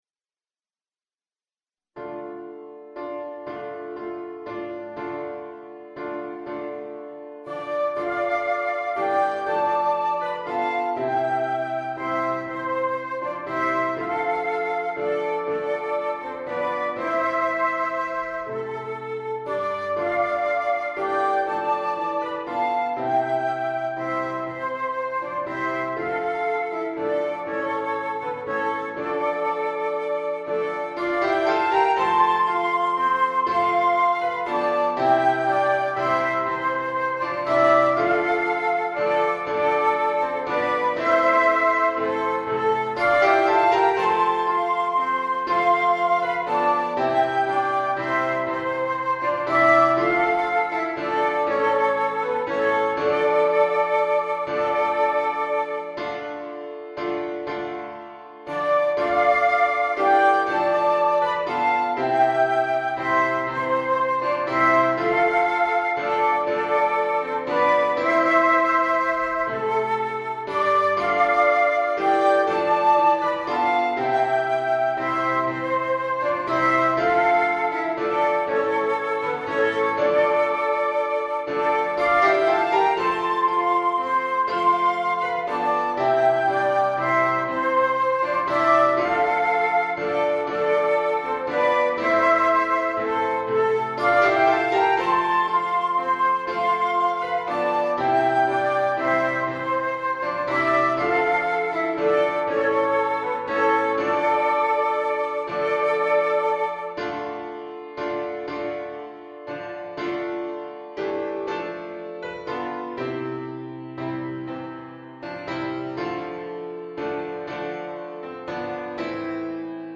The MP3 was recorded with NotePerformer.
Folk and World